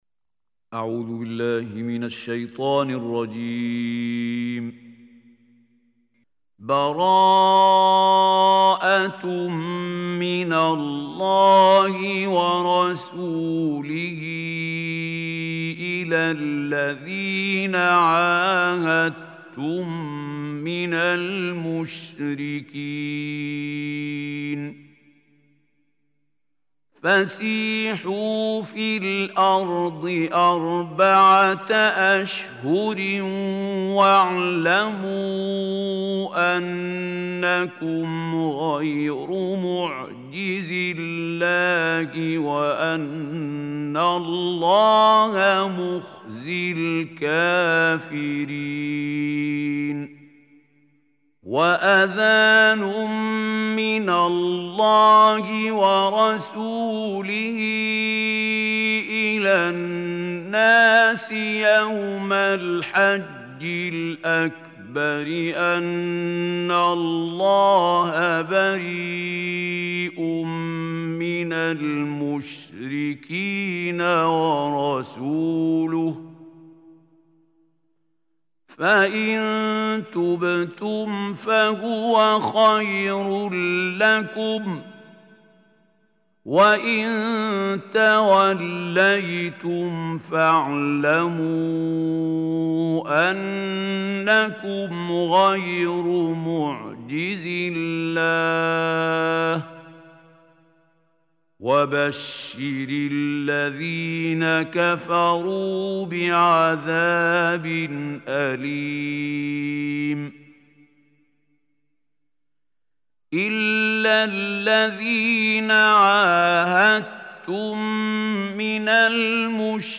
Surah Tawbah Recitation by Mahmoud Khalil Hussary